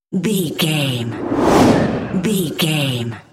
Whoosh airy
Sound Effects
Atonal
futuristic
whoosh